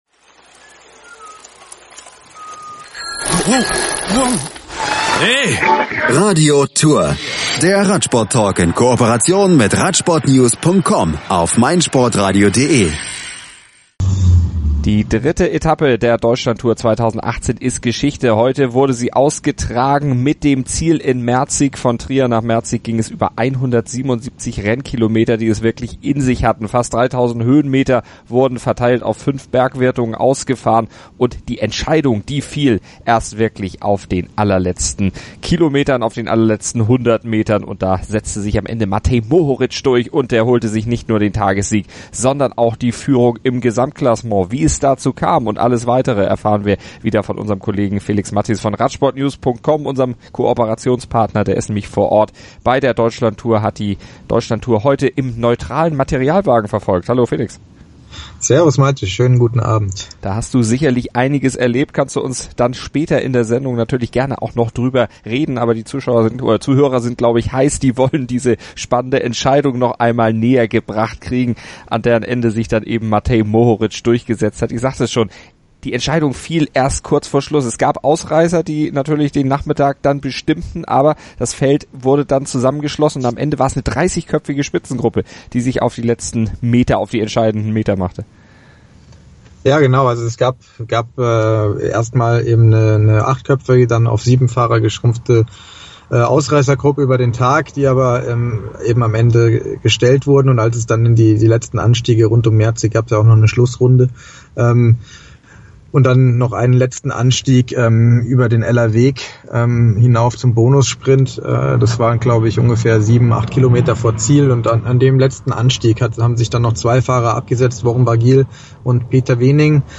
Außerdem haben sie den gestürzten Lennart Kämna, den entthronten Maximilian Schachmann sowie den heute relativ stressfreien Pascal Ackermann im Interview. Und der Tageszweite Nils Politt lässt seiner Enttäuschung über den knapp verpassten Etappensieg freien Lauf.